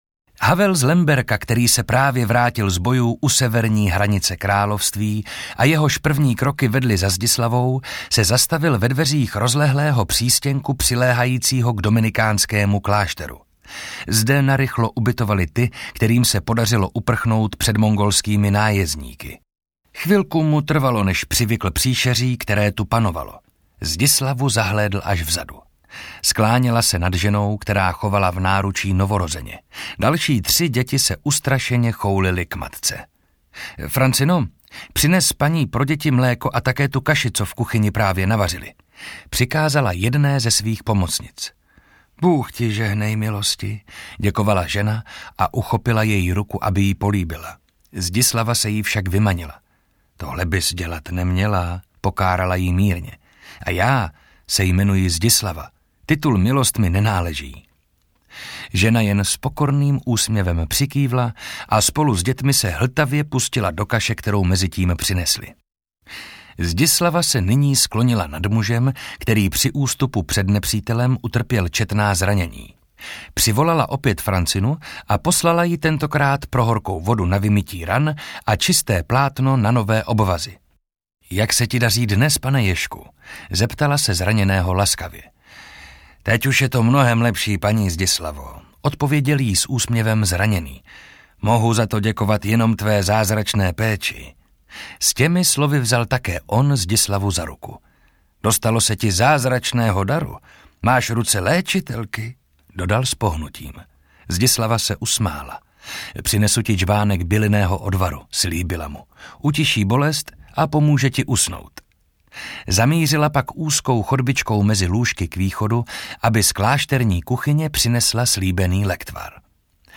Ukázka z knihy
natočeno ve zvukovém studiu Karpofon (AudioStory)